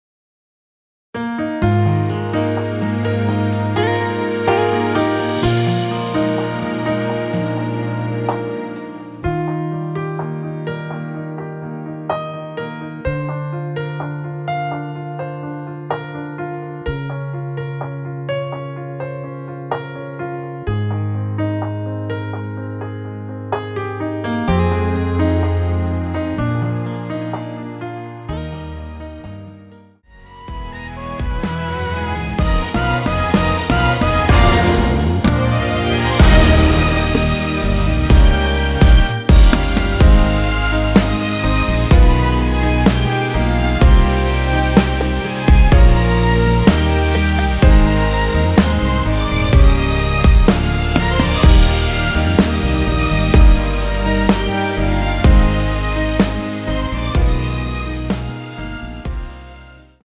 Eb
앞부분30초, 뒷부분30초씩 편집해서 올려 드리고 있습니다.
중간에 음이 끈어지고 다시 나오는 이유는